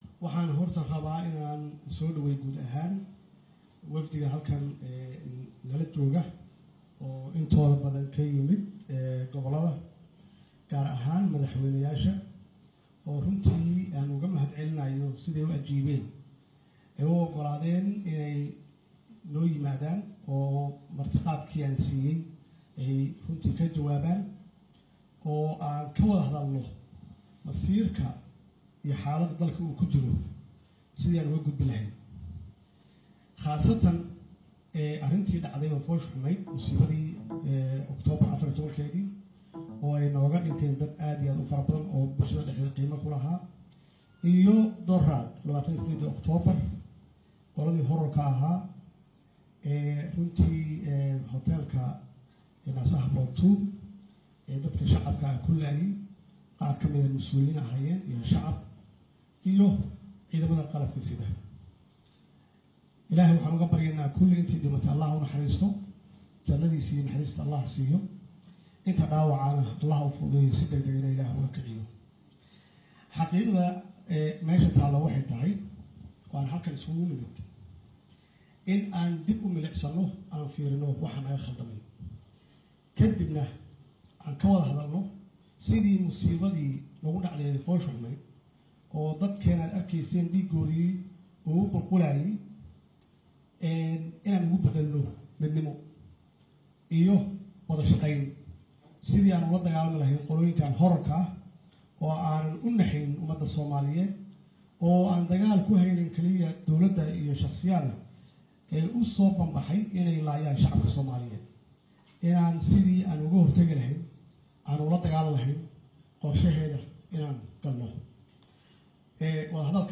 The whole speeches by leaders meeting in Mogadishu.